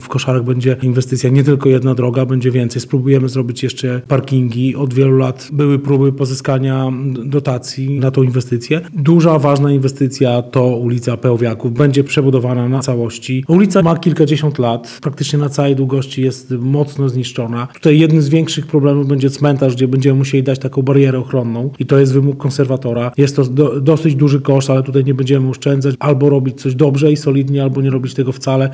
– Na obie te inwestycje mieszkańcy czekają od dawna – podkreśla prezydent Zamościa Rafał Zwolak.